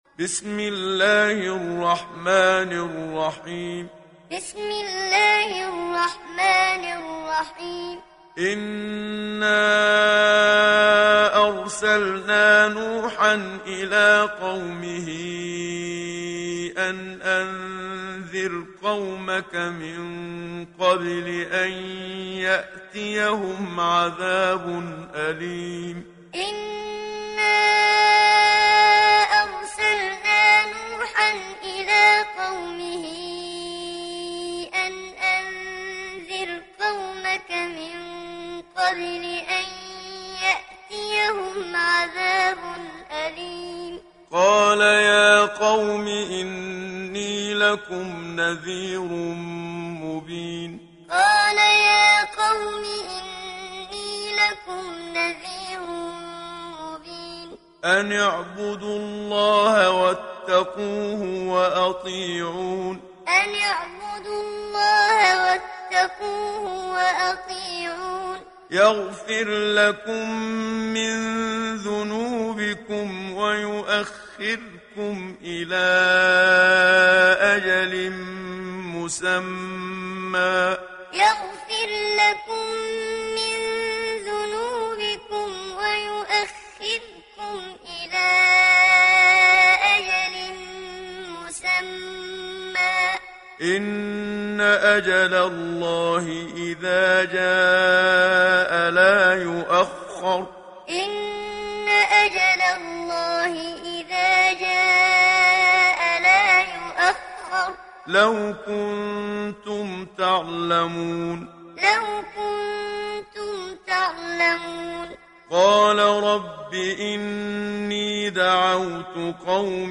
Surat Nuh Download mp3 Muhammad Siddiq Minshawi Muallim Riwayat Hafs dari Asim, Download Quran dan mendengarkan mp3 tautan langsung penuh
Download Surat Nuh Muhammad Siddiq Minshawi Muallim